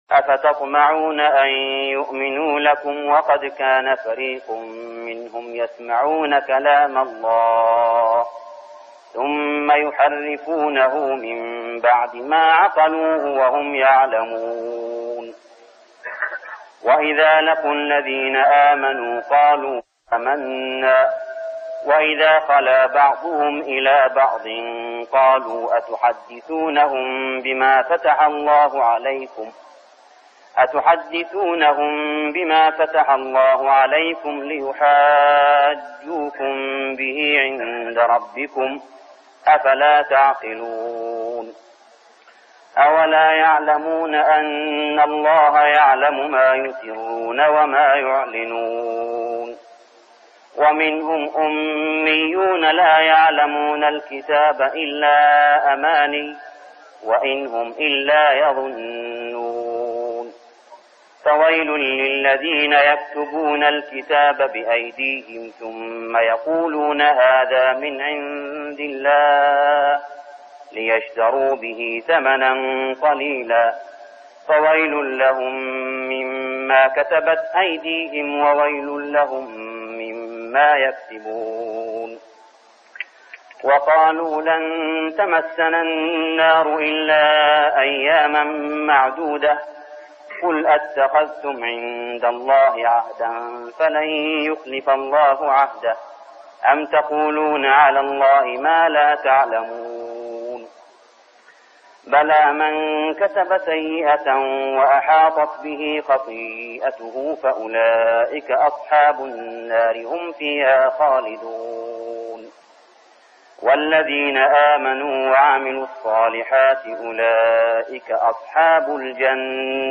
من ليالي رمضان 1405هـ سورة البقرة 75-126 | From nights of Ramadan Surah Al-Baqarah > تراويح الحرم المكي عام 1405 🕋 > التراويح - تلاوات الحرمين